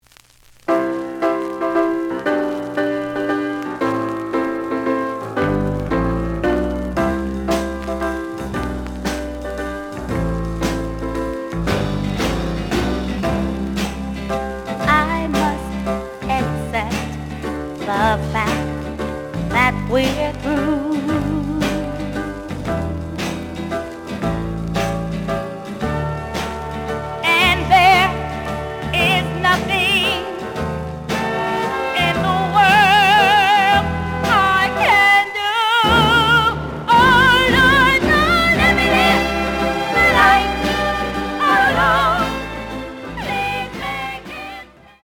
The audio sample is recorded from the actual item.
●Genre: Soul, 60's Soul
Slight click noise on both sides due to a bubble.)